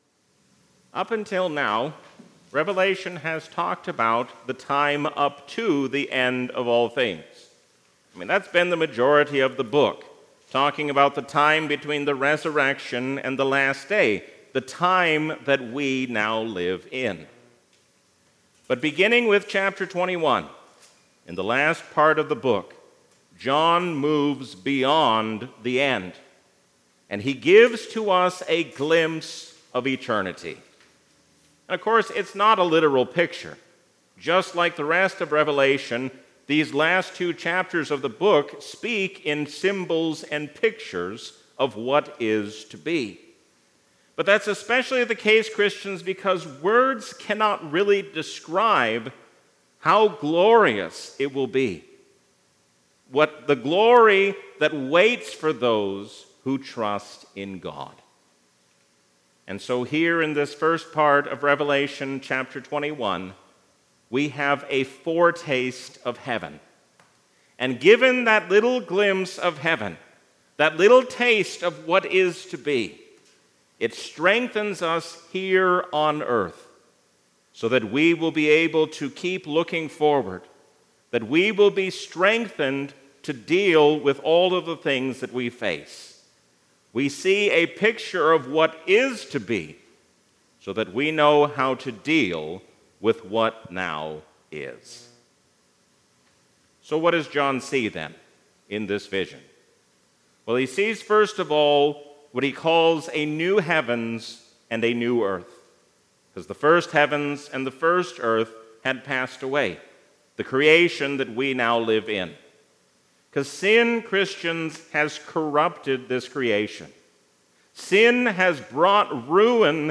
A sermon from the season "Trinity 2024." If we want to be bold for God, we need only come together and call on the Lord who is our strength, and He will answer us.